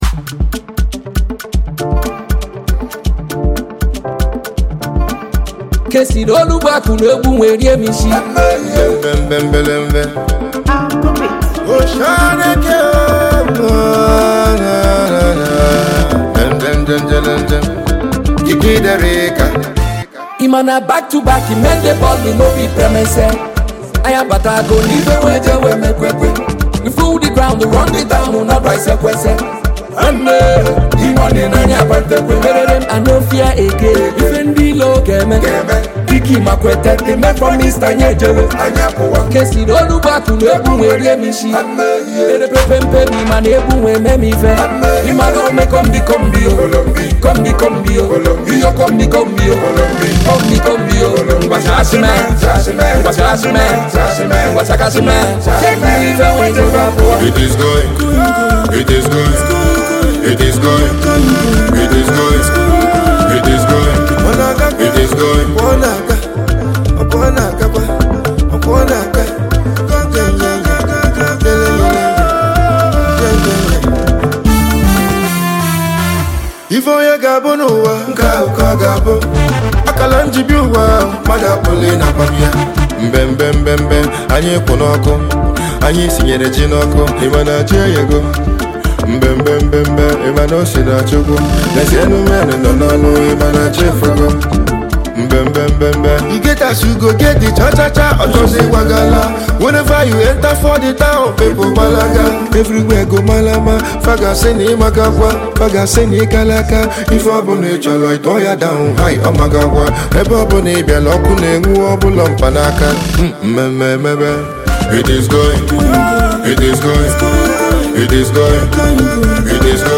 Ojapiano singer